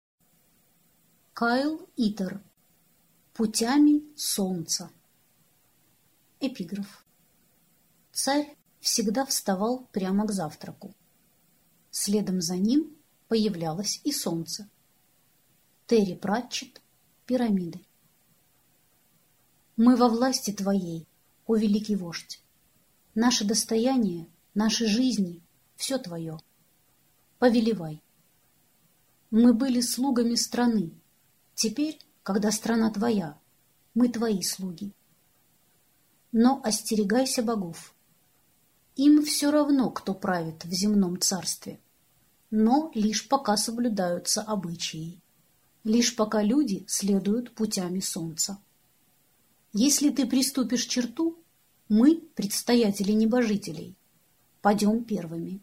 Аудиокнига Путями солнца | Библиотека аудиокниг
Прослушать и бесплатно скачать фрагмент аудиокниги